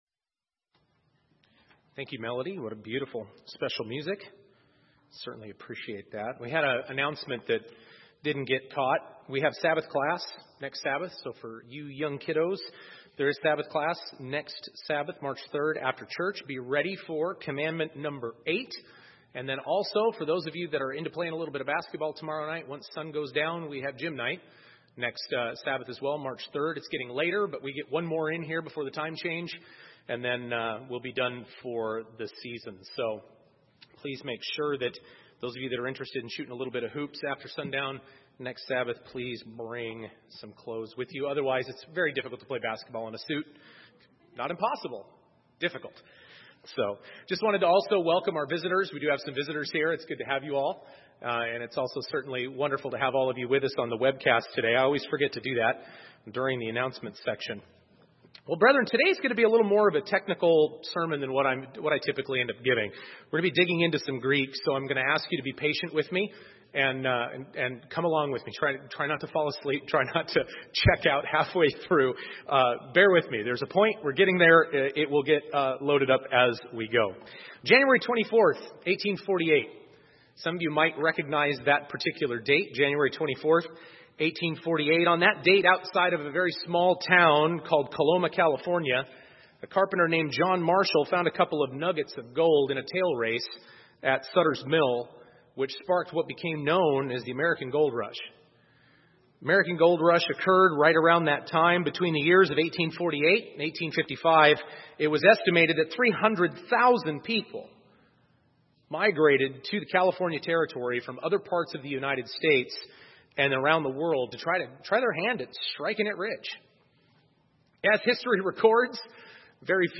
The Refiner's Fire | United Church of God